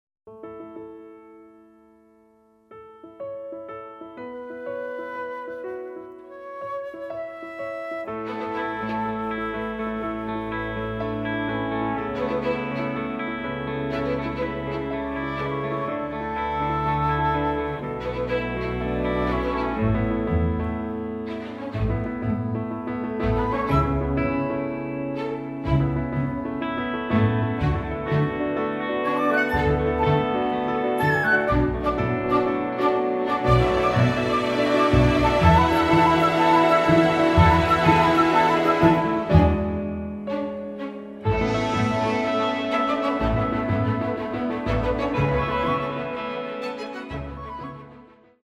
Recording done at Air Studios in London.